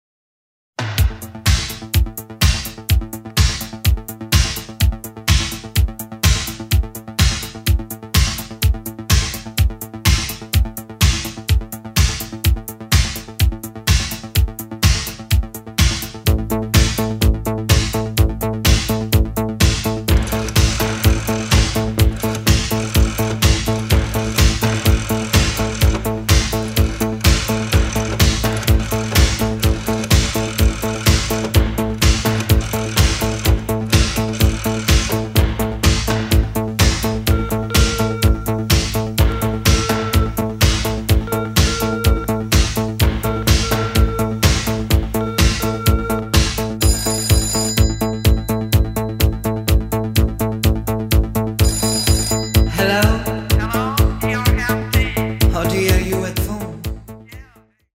Reissue of this italo disco rarity from 1986.